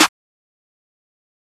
Southside Clap (3).wav